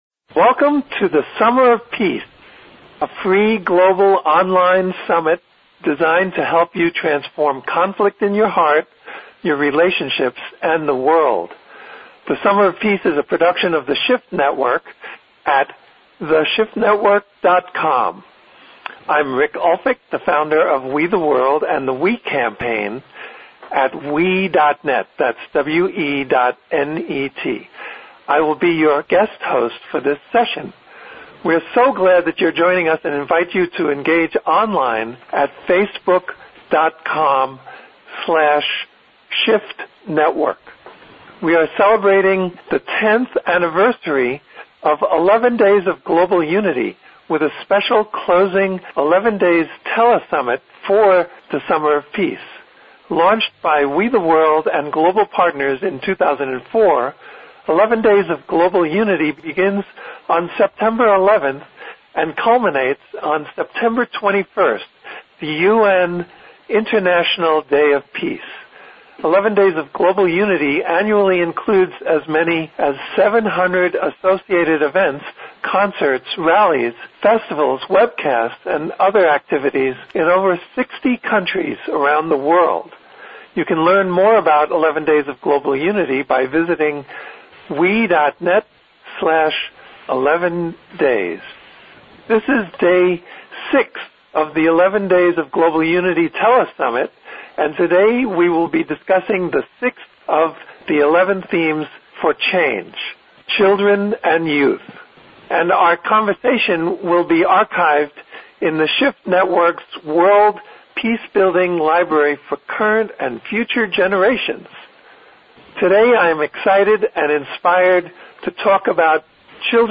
The entire conference was jam-packed with amazing speakers.